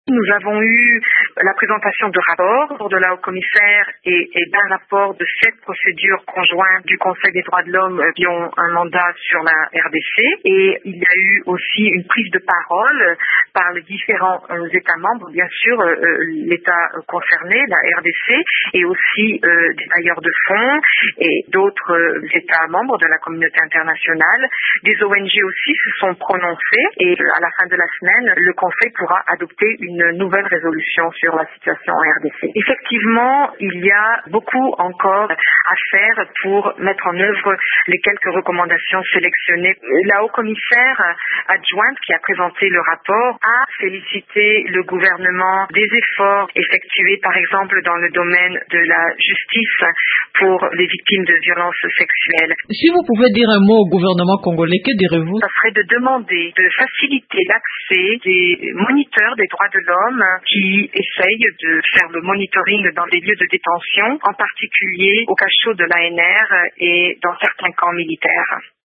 Elle l’explique à Radio Okapi :